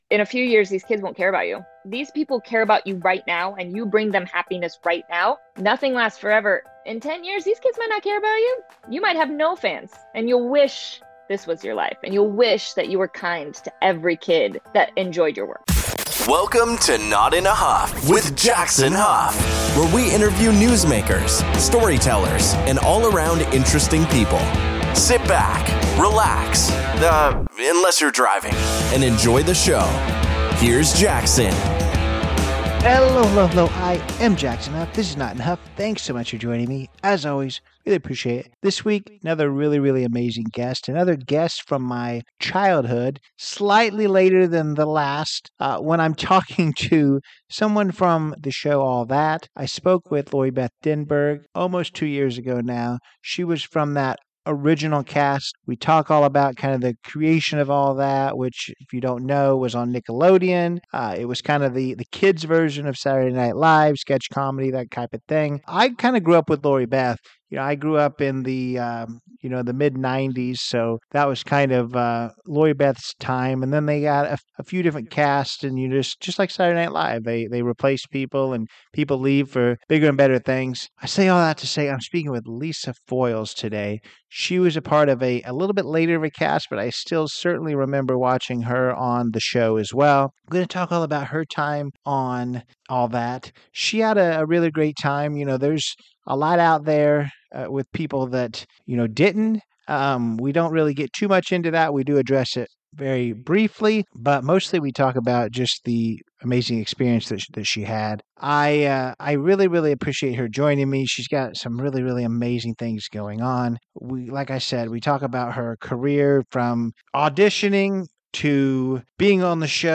Tune in for a fun and insightful conversation with Lisa